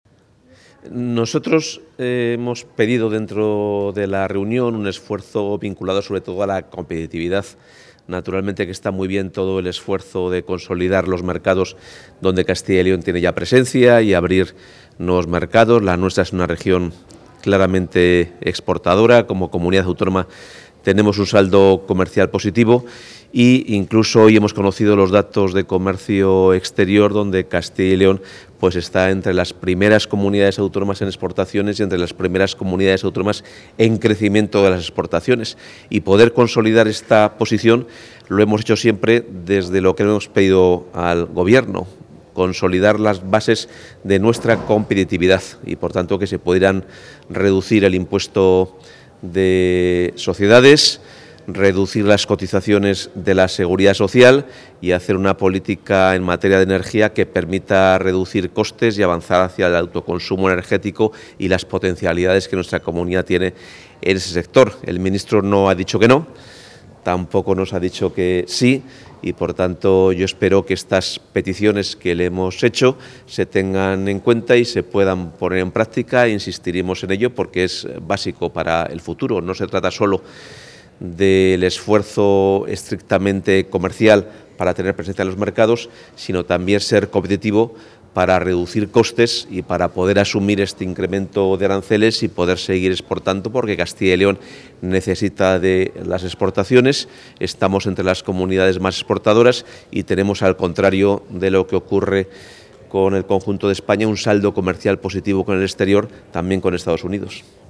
Valoración del consejero de Economía y Hacienda tras el Consejo Interterritorial de Internacionalización